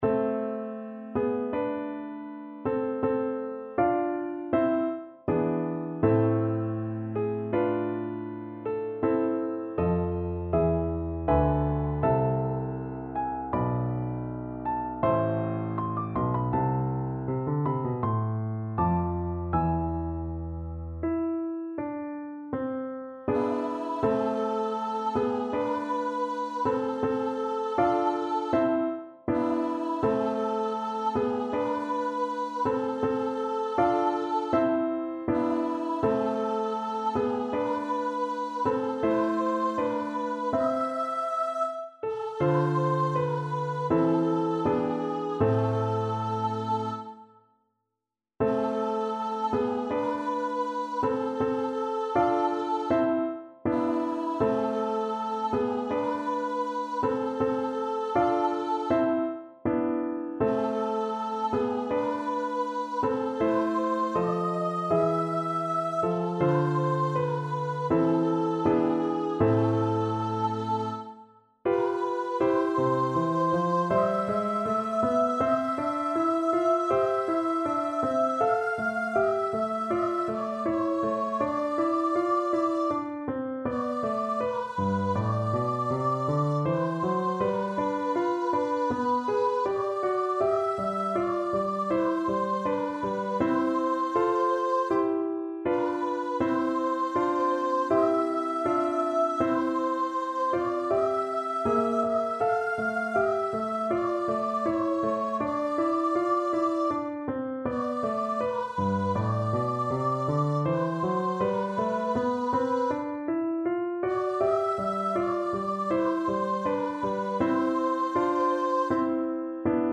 Voice
A minor (Sounding Pitch) (View more A minor Music for Voice )
4/4 (View more 4/4 Music)
Andante =c.80
Classical (View more Classical Voice Music)